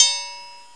1 channel
triangle.mp3